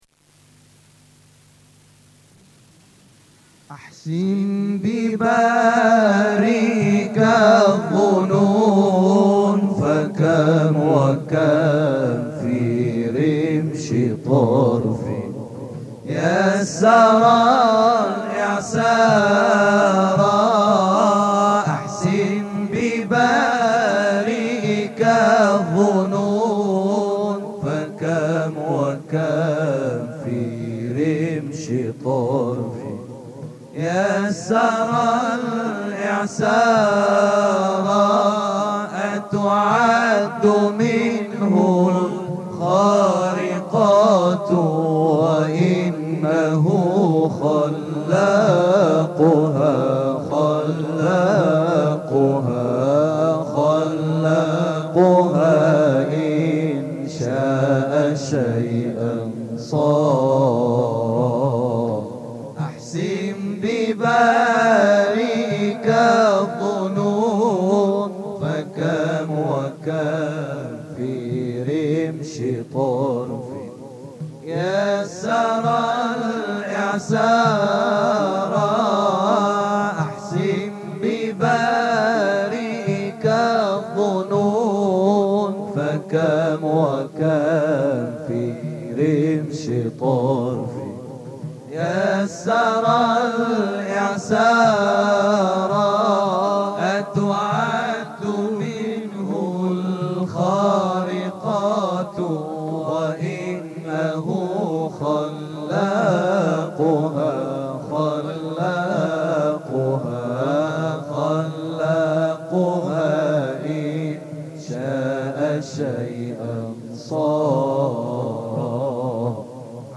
Aufnahme vom Maulid in der Moschee von Scheikh Abdullah al-Daghastani, Damaskus, Rabi´al-Awwal 1429 (MP3-Datei, 36 MB), 15.04.08